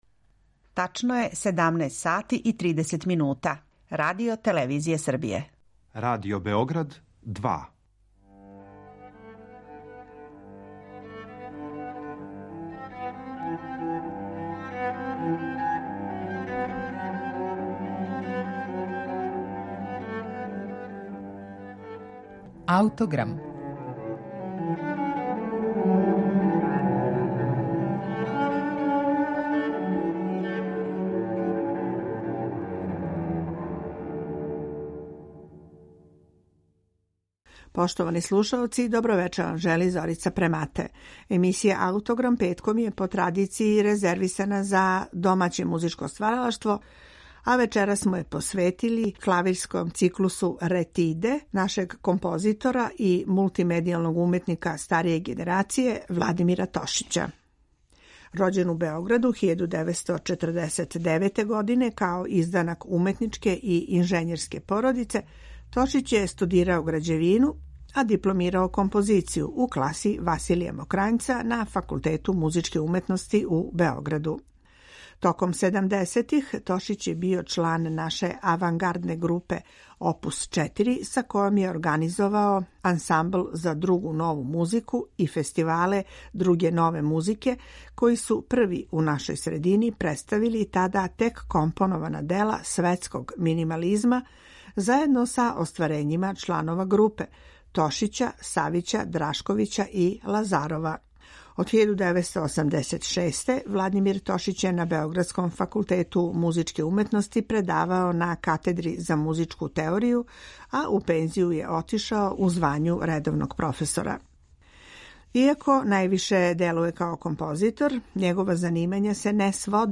клавирских етида нашег аутора старије генерације